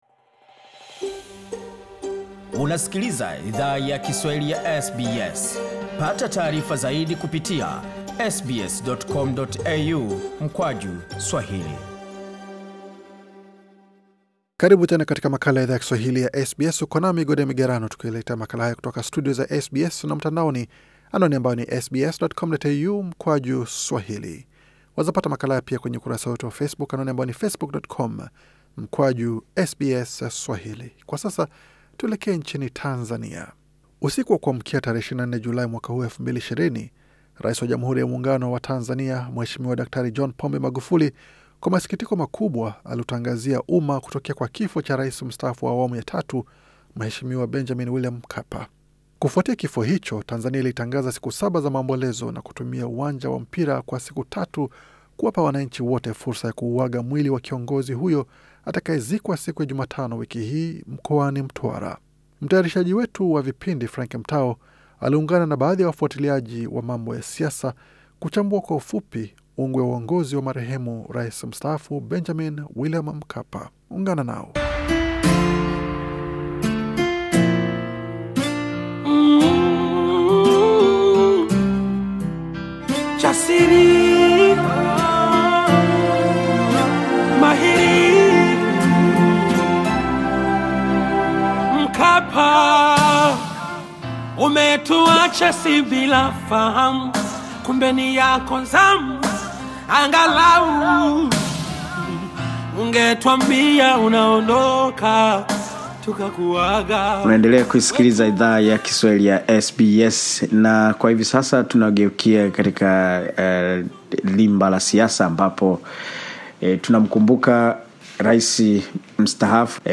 aliungana na baadhi ya wafuatiliaji wa mambo ya siasa kuchambua kwa ufupi ungwe ya uongozi wa Marehemu Rais Mstaafu Benjamin William Mkapa.